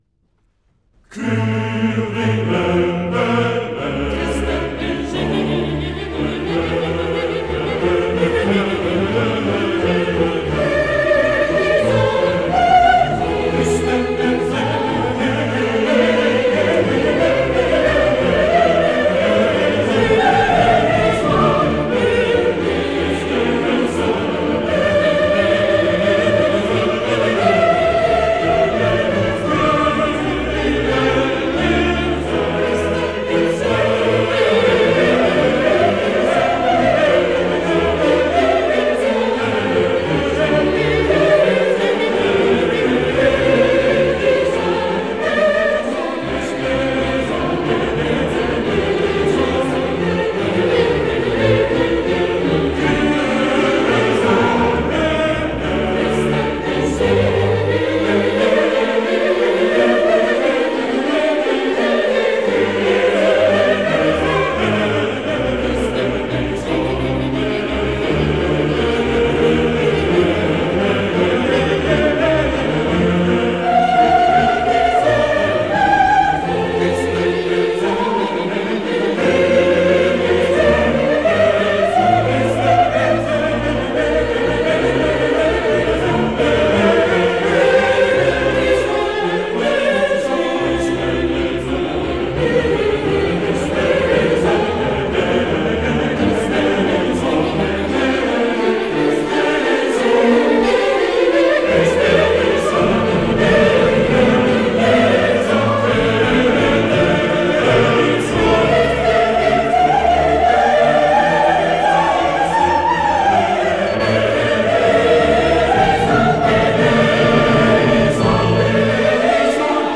Je vous laisse à l’écoute de deux pièces musicales provenant de notre héritage classique religieux, deux Kyrie bien différents.